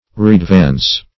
Readvance \Re`ad*vance"\ (r[=e]`[a^]d*v[.a]ns"), v. i. To advance again.